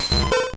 Cri de Ptitard dans Pokémon Or et Argent.